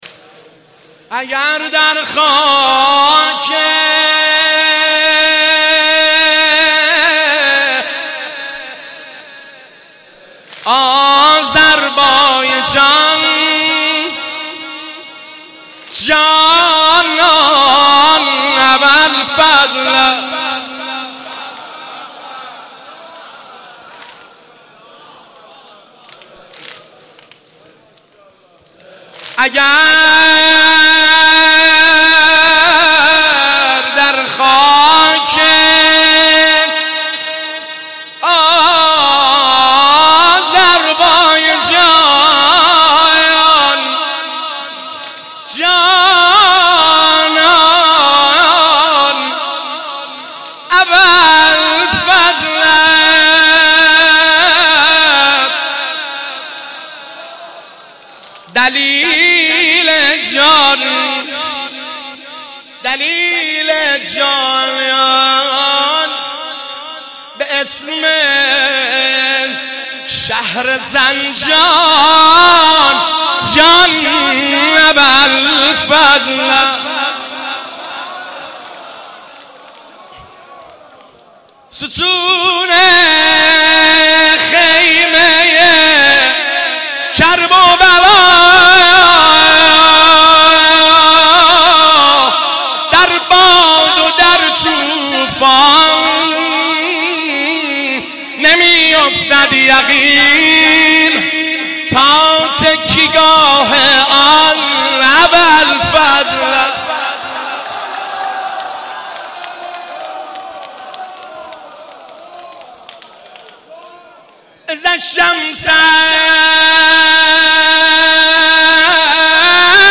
عقیق:شب عاشورا محرم95/هیئت غریب مدینه امیر کلا(بابل)
شعر